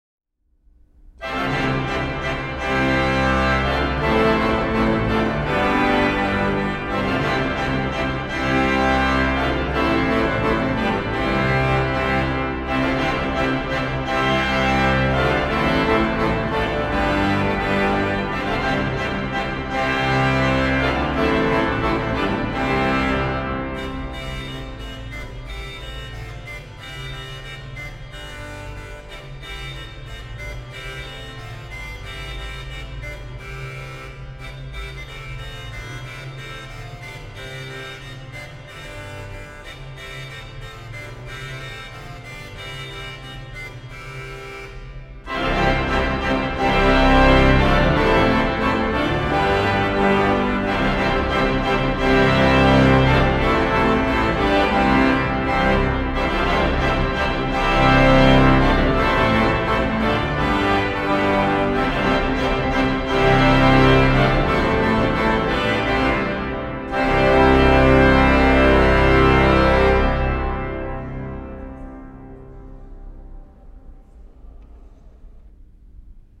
Improvisation: Jeux des FlutesDatei-Informationen